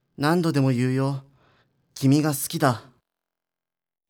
ボイス
男性